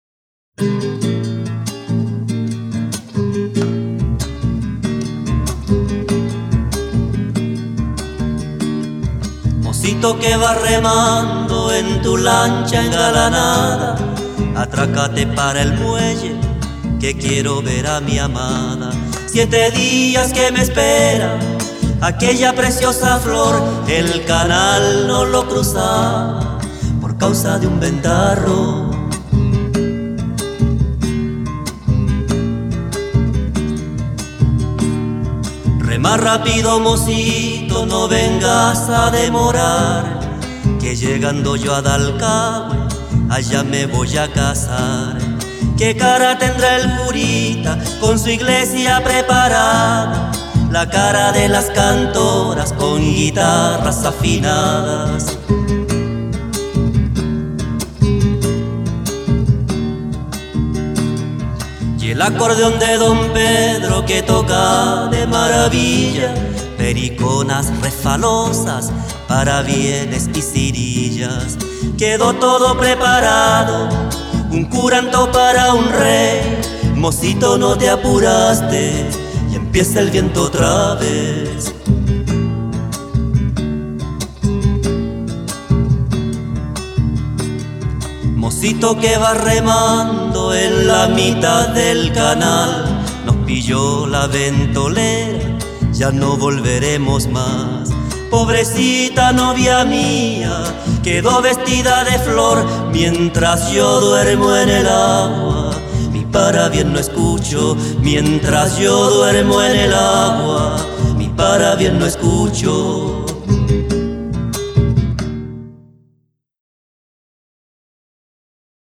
Canto
Música tradicional
Folklore